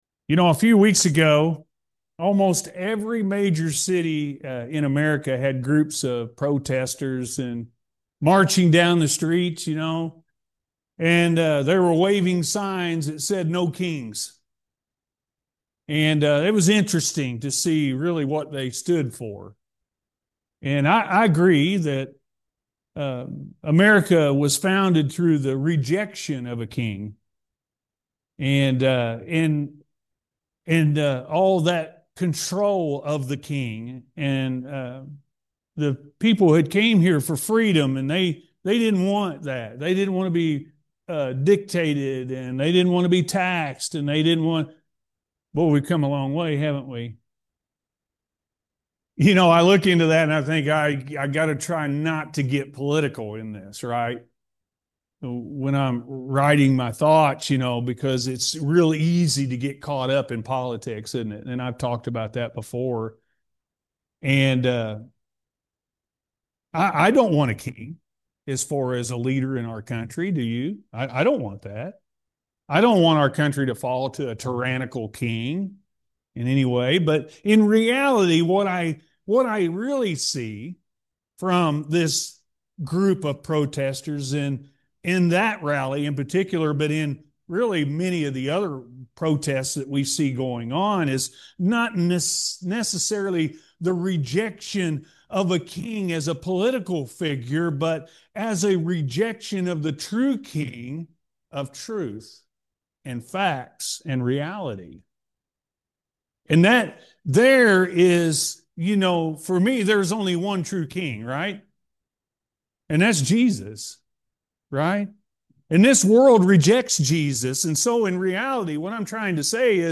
Only One True King-A.M. Service – Anna First Church of the Nazarene